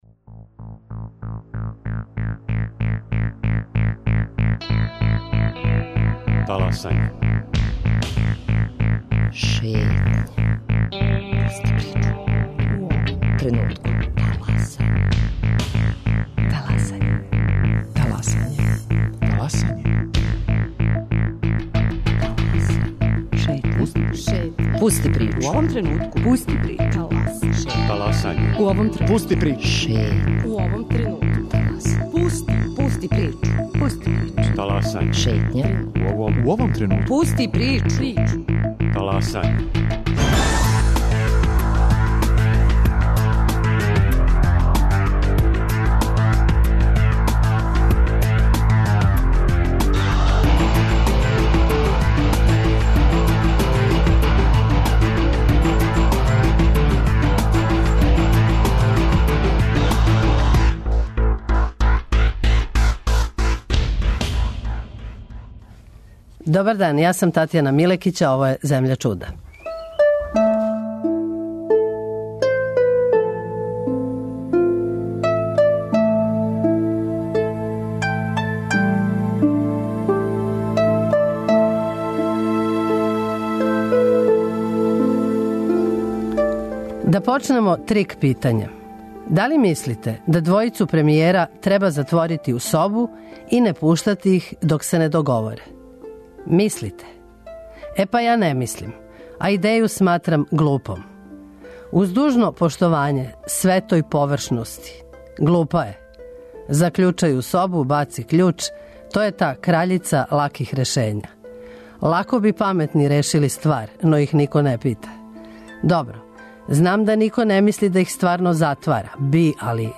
Свечане мисли и пригодни говори: